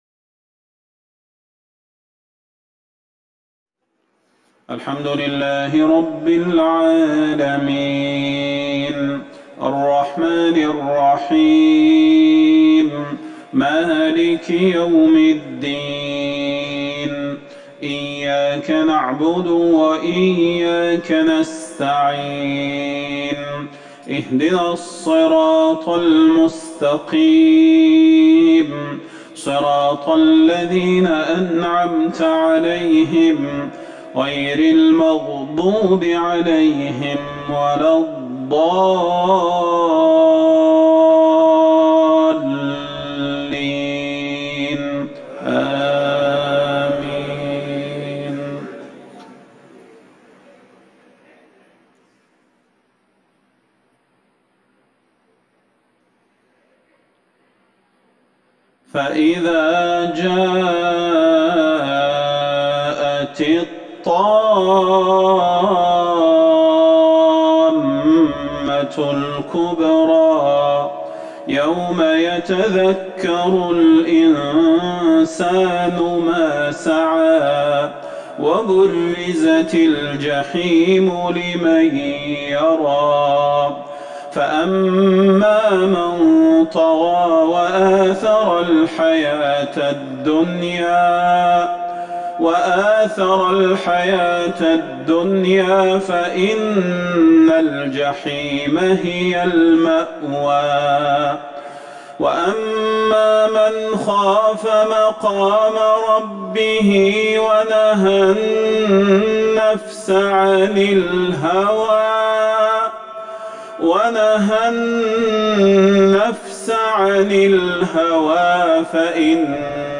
صلاة العشاء ٩ جمادي الاخره ١٤٤١هـ سورة النازعات وعبس Isha prayer 3-2-2020 from Surah An-Naza'at and Abs > 1441 🕌 > الفروض - تلاوات الحرمين